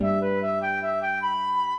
flute-harp
minuet9-6.wav